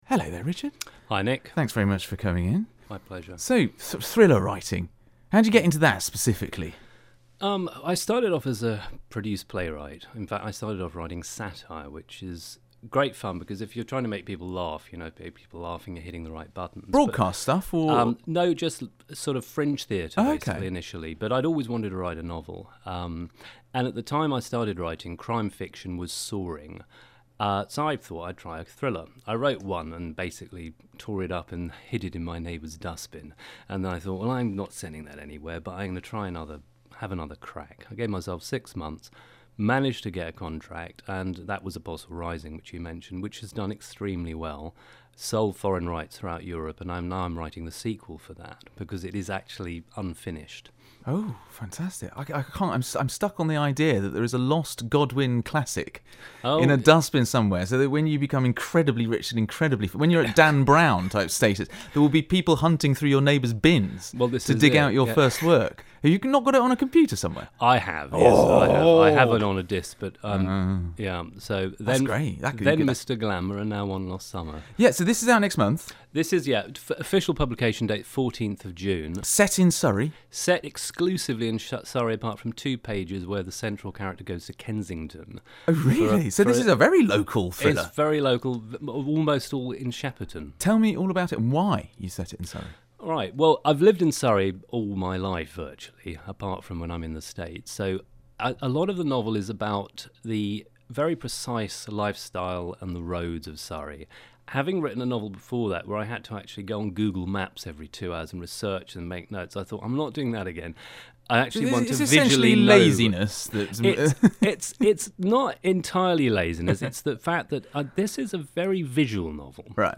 Short interview